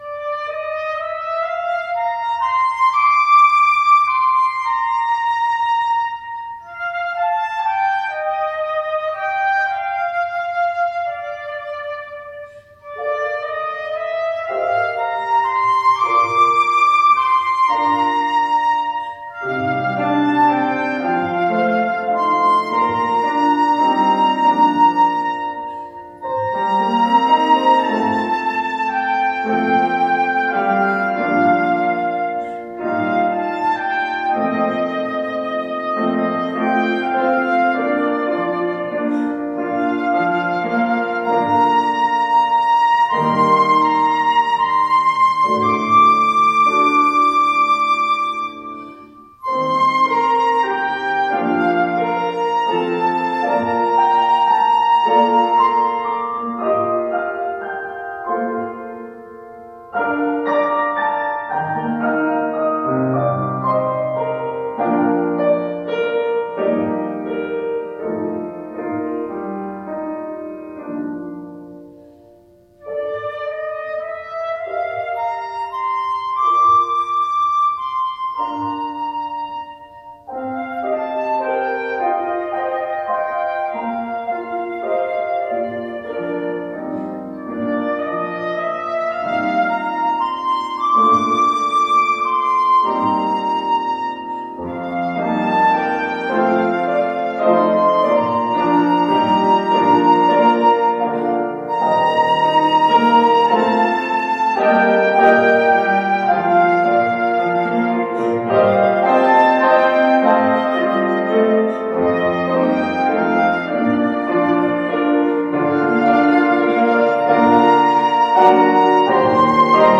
By Organist/Pianist
Flutist